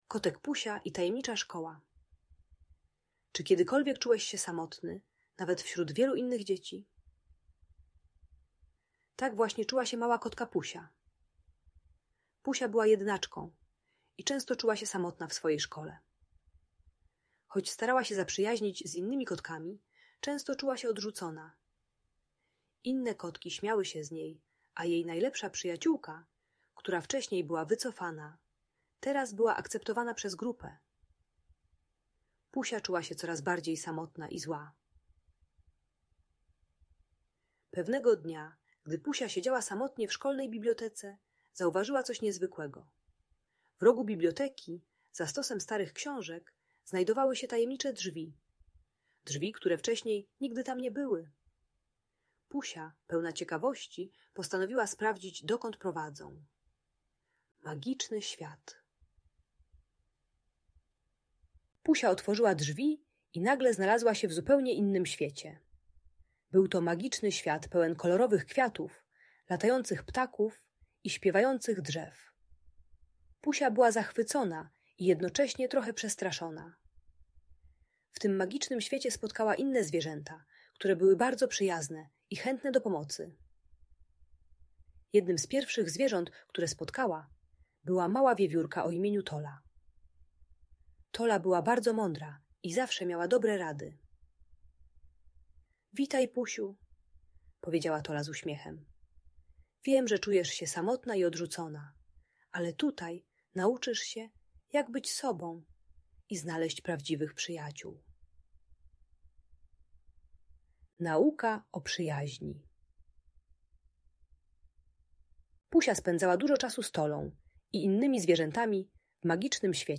Kotek Pusia i Tajemnicza Szkoła - Audiobajka